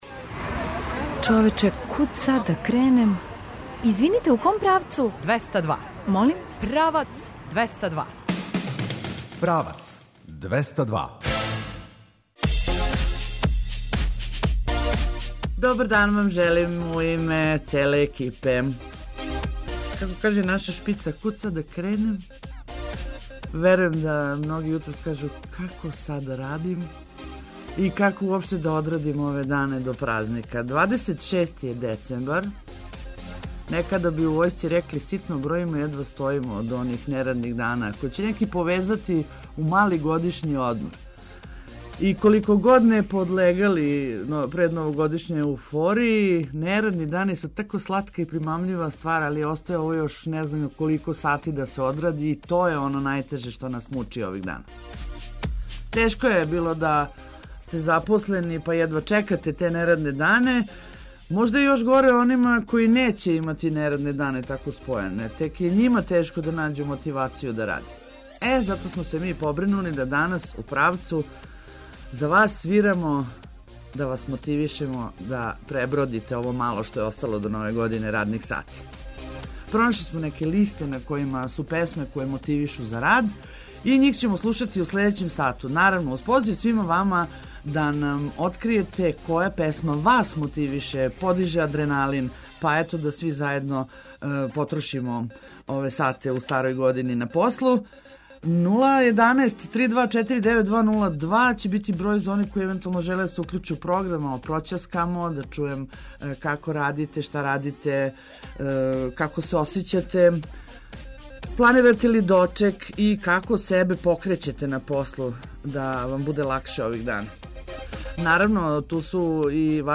Уз музику је све лакше поднети, па смо зато припремили листу песама које могу да мотивишу и на послу и у обављању свакодневних обавеза а предлоге и допуне очекујемо и од вас. Од 11 часова, дакле, играмо и певамо а у првом сату Правца повешћемо вас и Фестивал руско-српског занатства у галерији Прогрес.